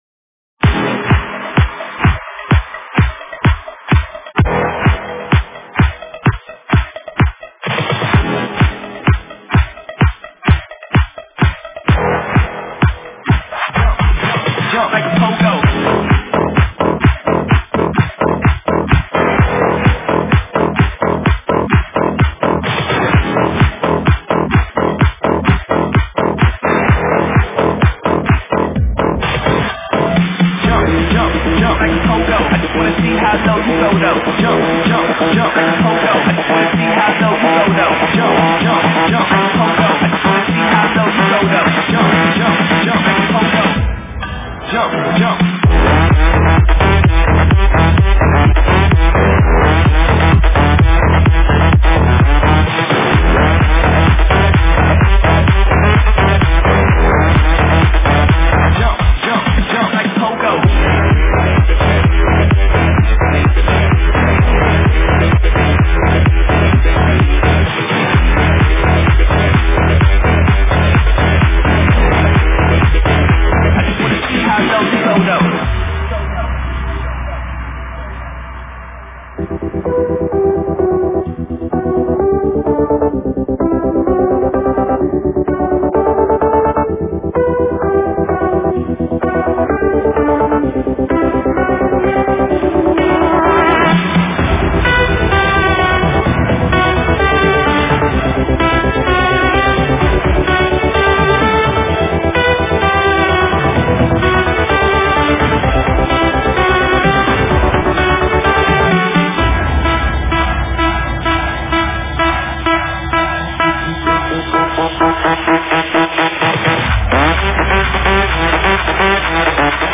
Стиль: Electro House / Electro / Dutch House